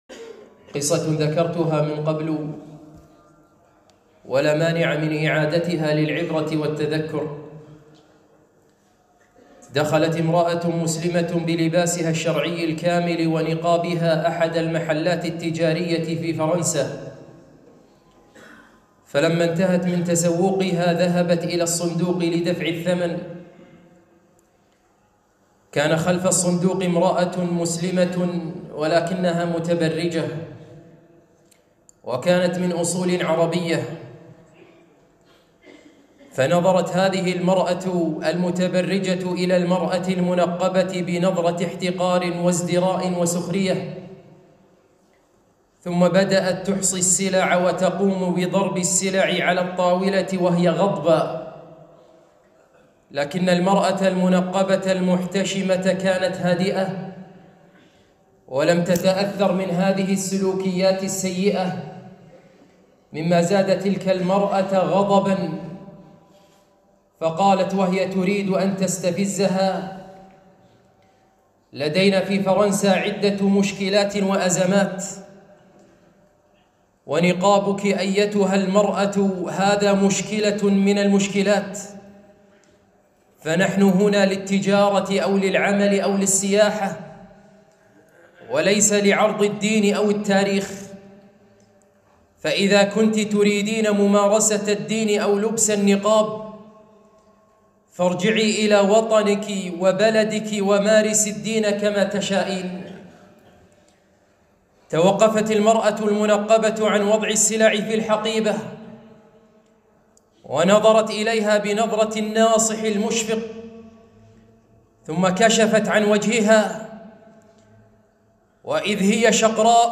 خطبة - قصة المرأة الفرنسية